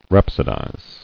[rhap·so·dize]